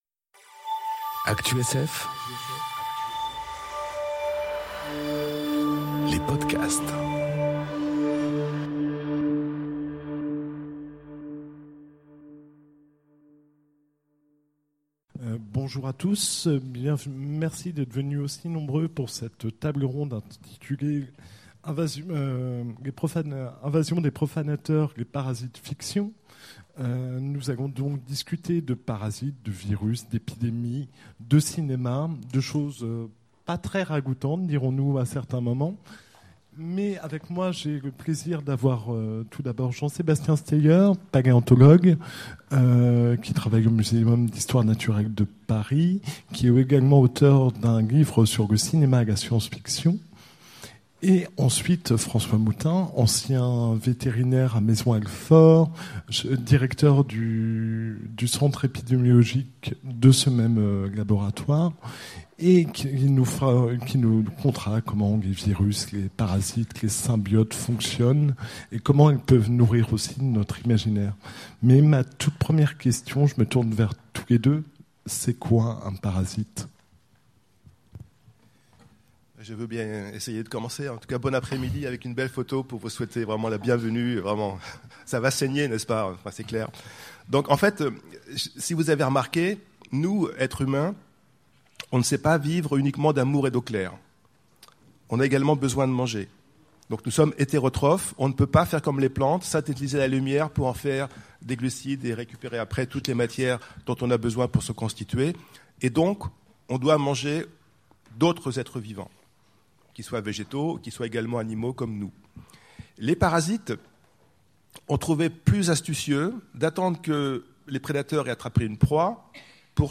Conférence L’Invasion des profanateurs : parasite-fictions enregistrée aux Utopiales 2018